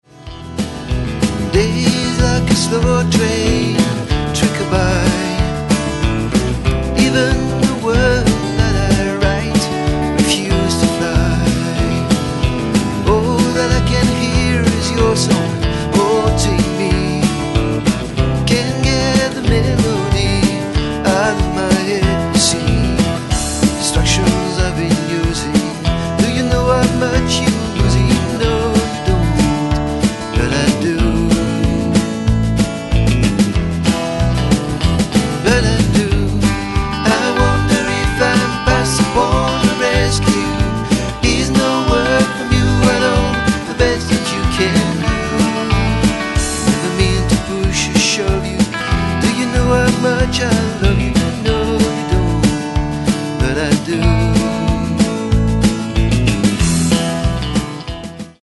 Basse, Chant, Harmonies
Banjo
Batterie
Guitare Electrique